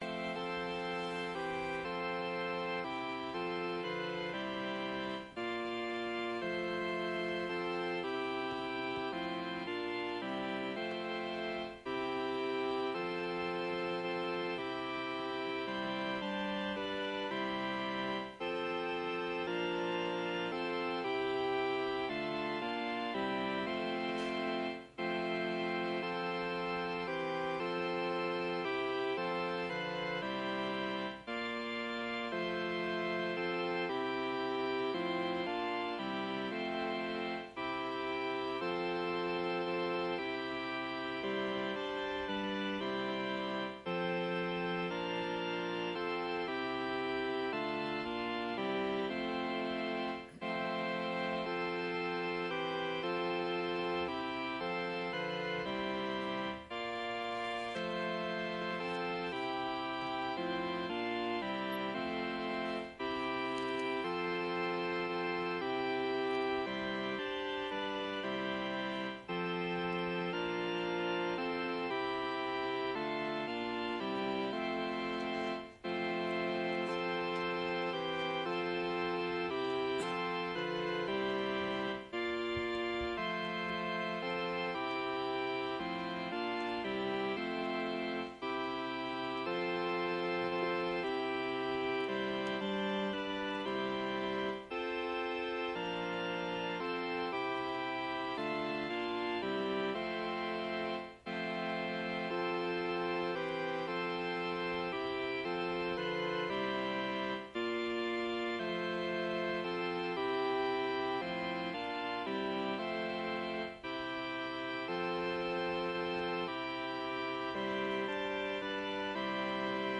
礼拝音源